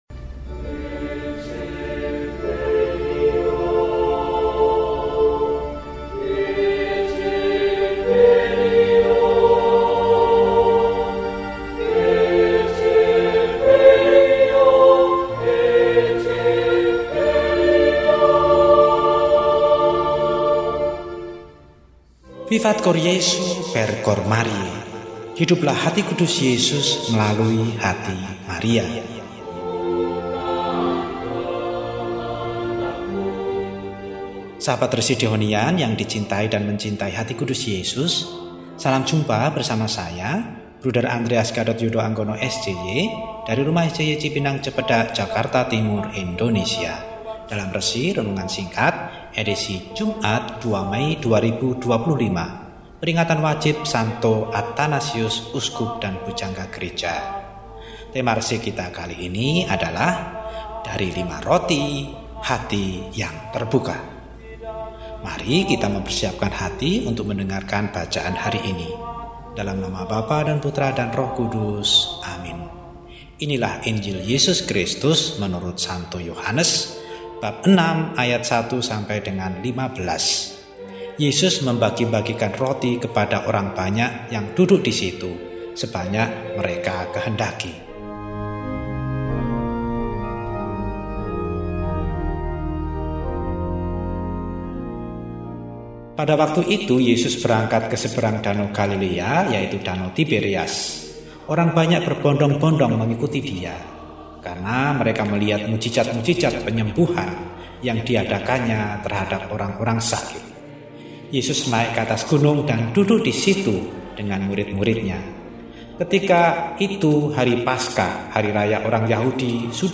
Jumat, 02 Mei 2025 – Peringatan Wajib St. Atanasius, Uskup dan Pujangga Gereja – RESI (Renungan Singkat) DEHONIAN